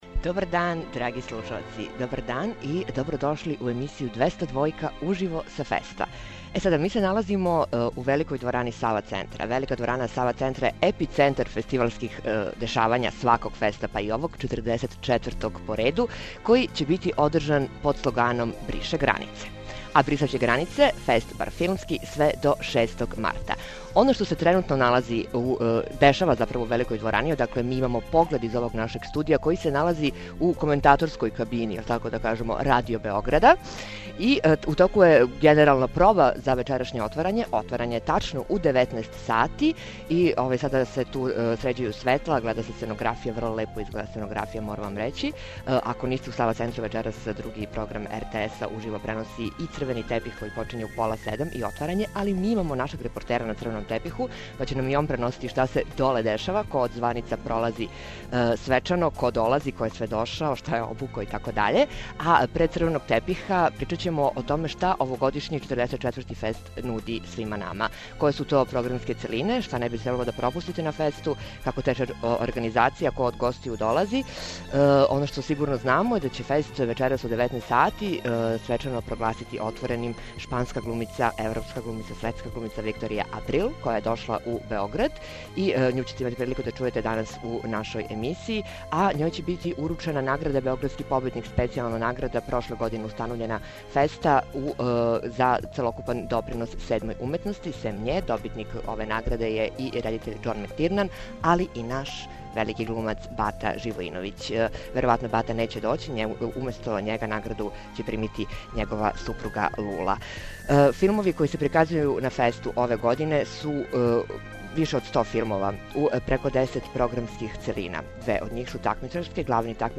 202 uživo sa FEST-a
U našem studiju u Sava Centru, odakle se pruža pogled na Veliku dvoranu u kojoj će u 19.00 svečano biti otvoren 44. Fest, ugostićemo organizatore najvećeg filmskog festivala kod nas, glumce, reditelje čiji su filmovi u programu, a naš reporter će se javiti sa crvenog tepiha kojim će prošetati najveće domaće, ali i inostrane filmske zvezde.